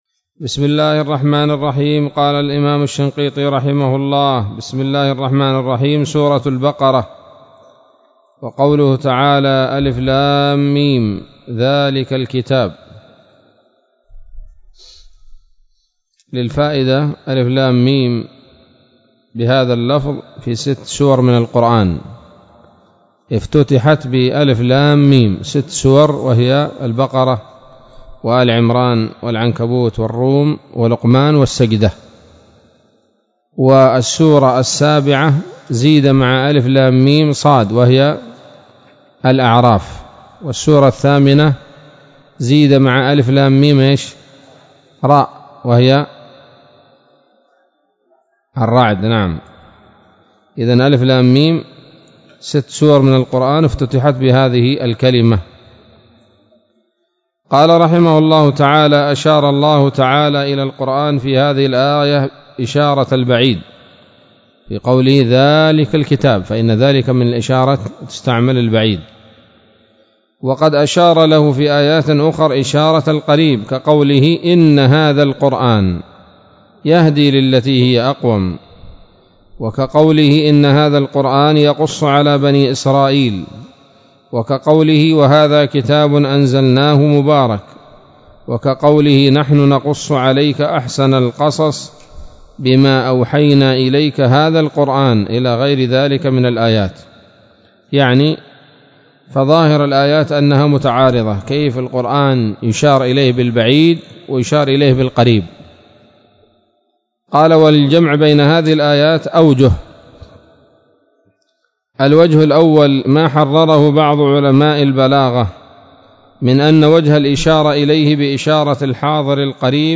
الدرس الثاني من دفع إيهام الاضطراب عن آيات الكتاب